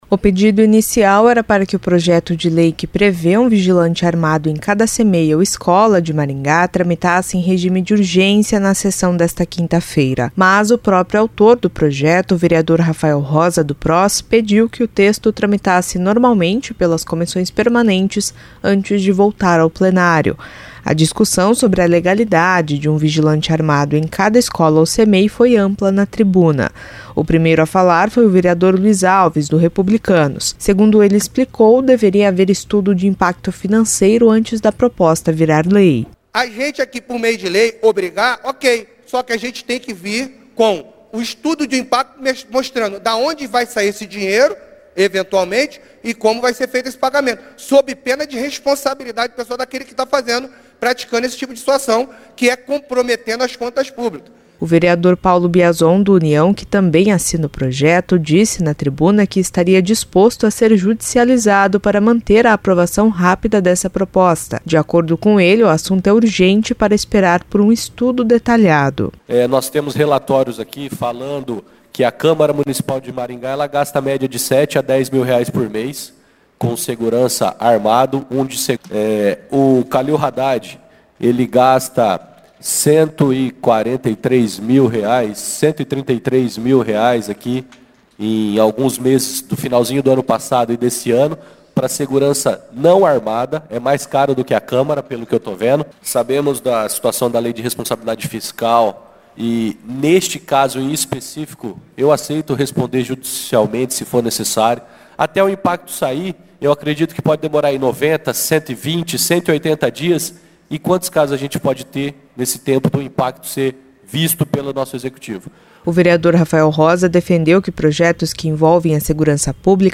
A discussão sobre a legalidade de um vigilante armado para cada cmei ou escola de Maringá foi ampla na tribuna.
O primeiro a falar foi o vereador Luiz Alves.